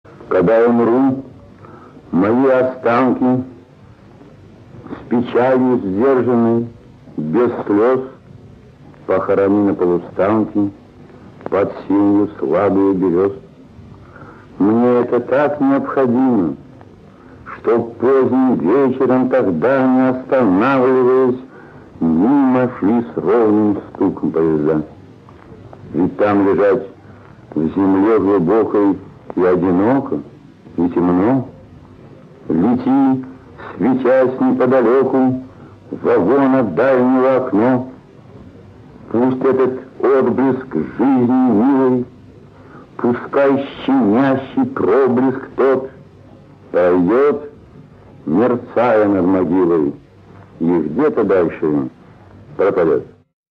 1. «Ярослав Смеляков – Попытка завещания (читает автор)» /
yaroslav-smelyakov-popytka-zaveshhaniya-chitaet-avtor